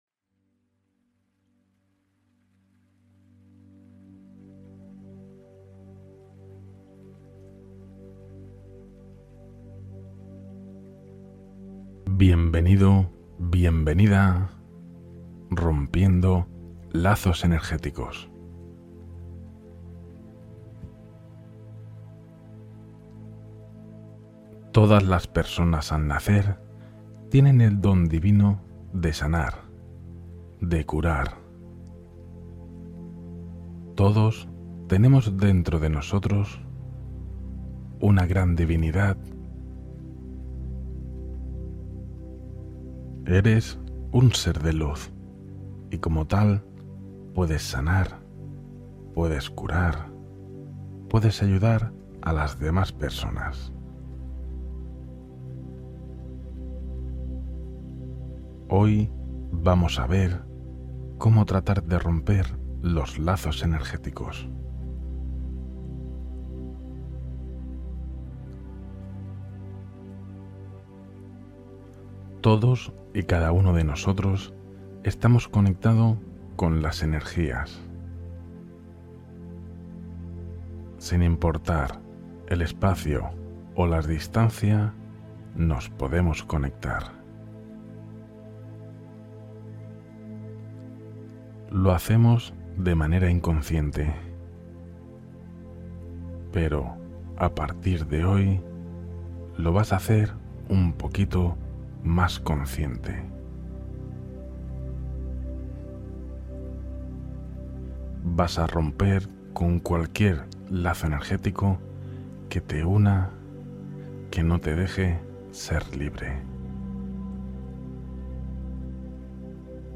Meditación para liberar lazos emocionales y energéticos antes de dormir